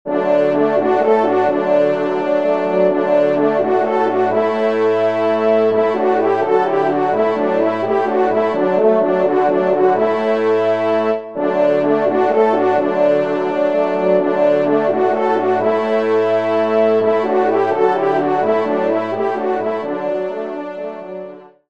Genre :  Divertissement pour Trompes ou Cors
ENSEMBLE